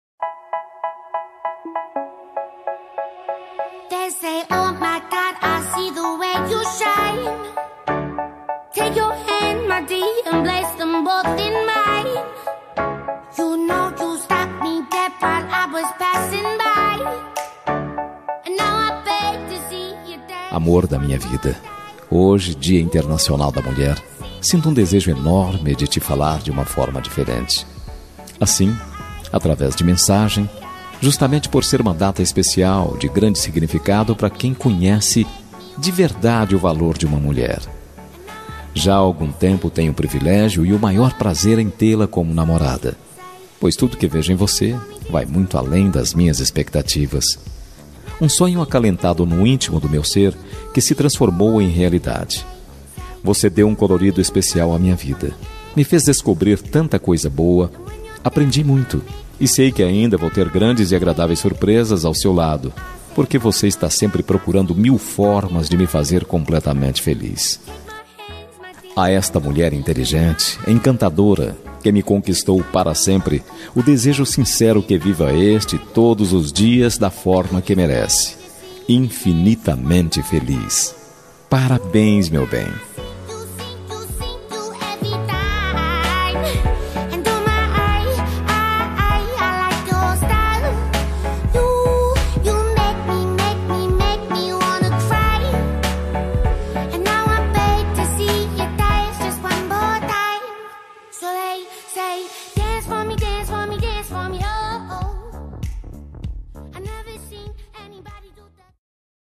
Dia das Mulheres Para Namorada – Voz Masculina – Cód: 53010